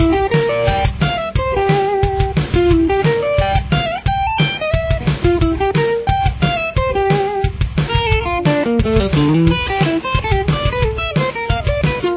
Intro Riff
Guitar 2